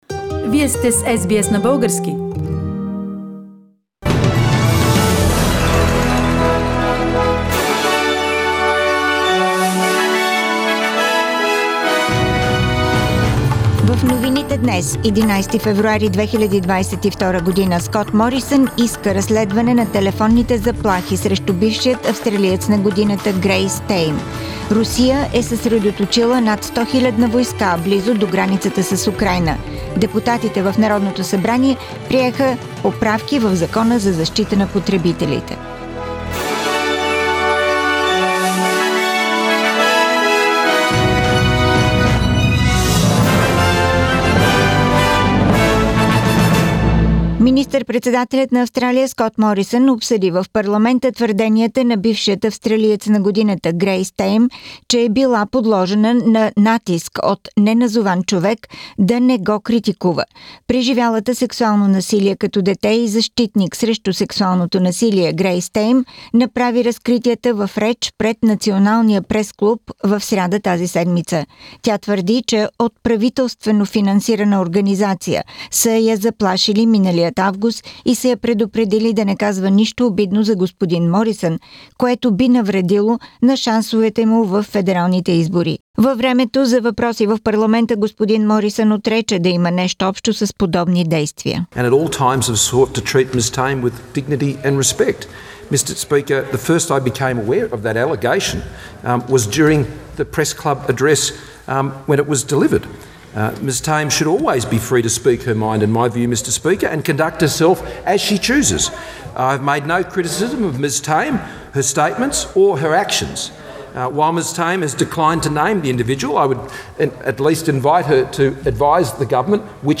Weekly Bulgarian News – 11th February 2022